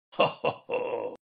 Talking Ben Laughing Sound Effect Free Download
Talking Ben Laughing